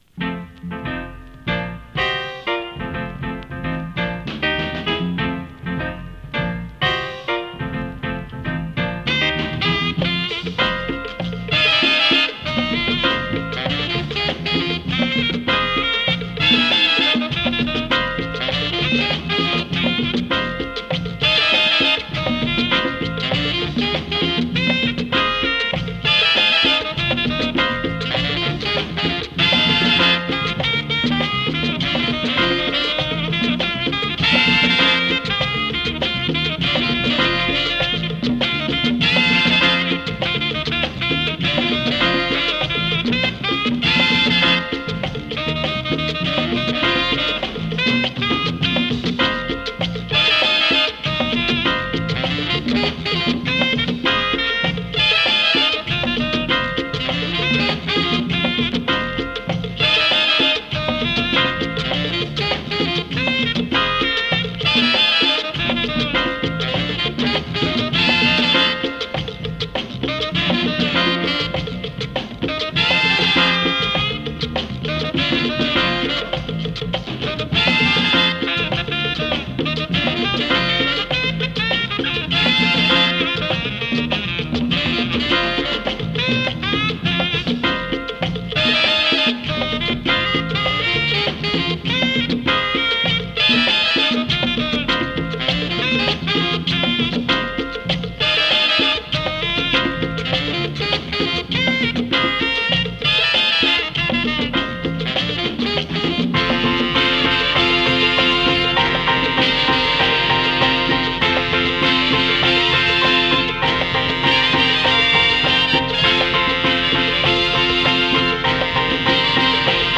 Voc入り 猥雑 ブーガルー